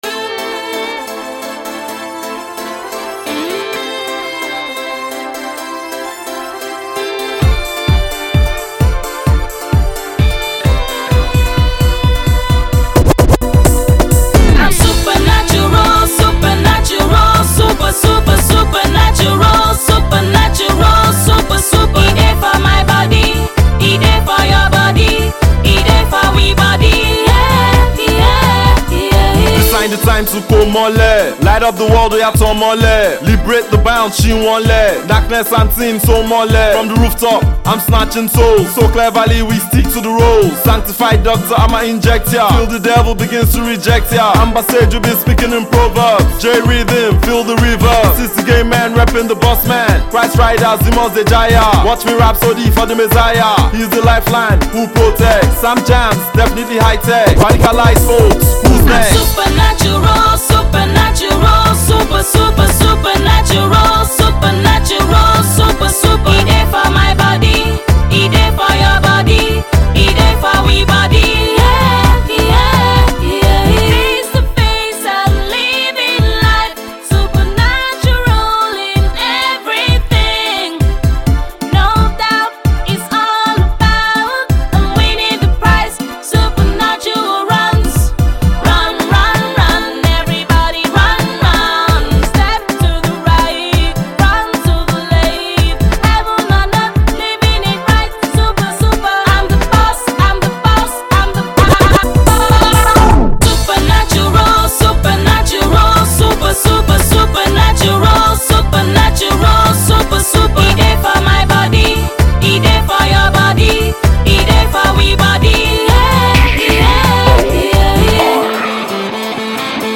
Nigerian Hip Hop couple
uber gifted guitarist
up-tempo tune